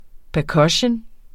Udtale [ pəˈkʌɕən ]